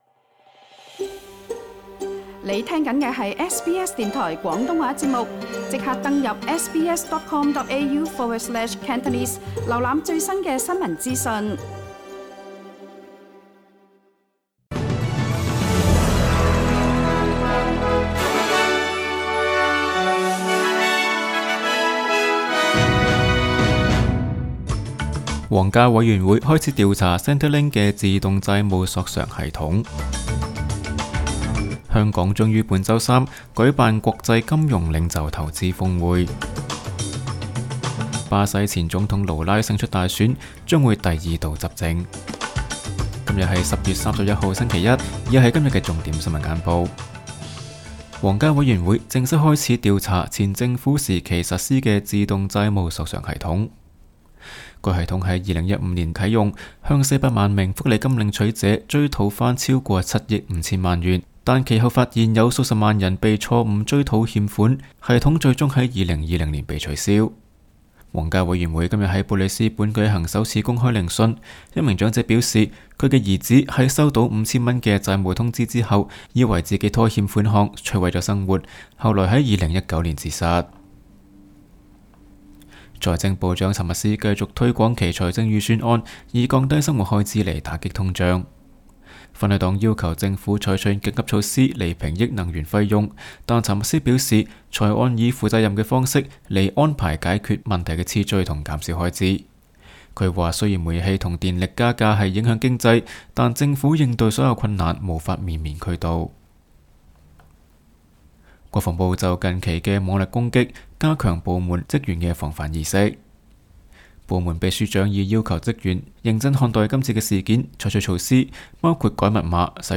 SBS 廣東話節目新聞簡報 Source: SBS / SBS Cantonese